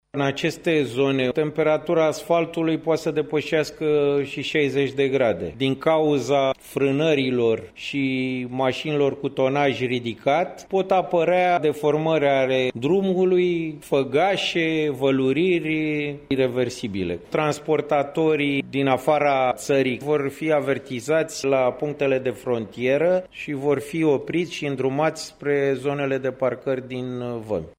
Interdicţia se aplică inclusiv pentru autovehiculele de transport care urmează să intre în ţară, a declarat la Radio România Actualităţi